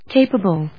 音節ca・pa・ble 発音記号・読み方
/kéɪpəbl(米国英語), ˈkeɪpʌbʌl(英国英語)/